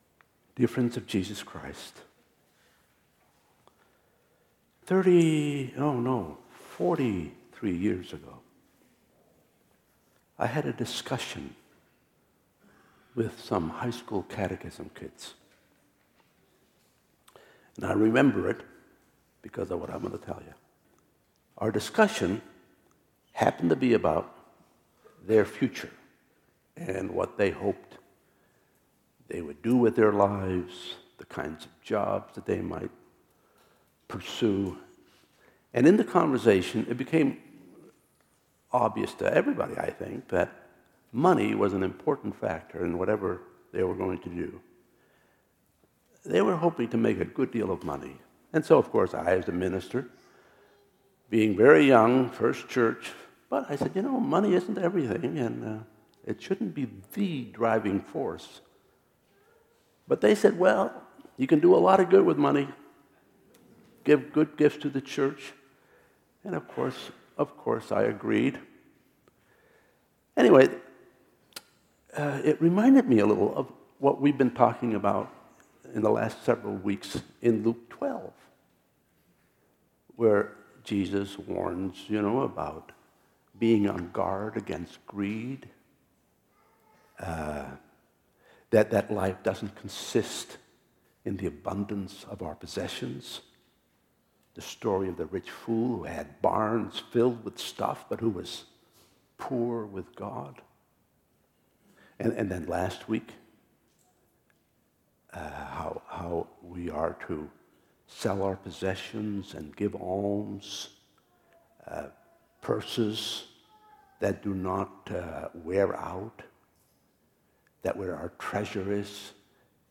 2016 Sermons